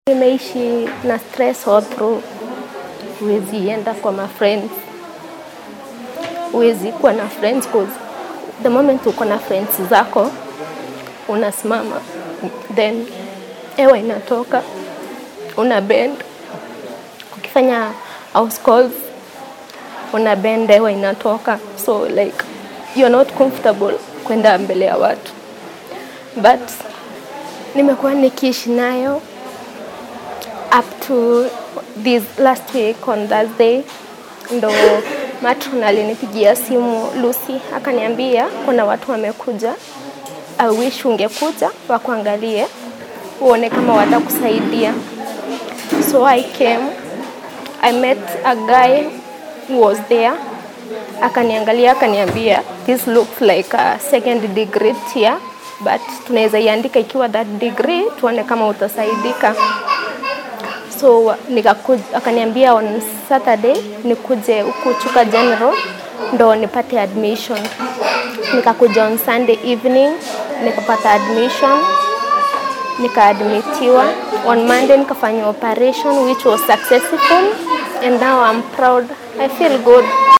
Mid ka mid ah haweenka barnaamijyada noocan ah ka faa’iidaystay ayaa dareenkeeda warbaahinta la wadaagtay. https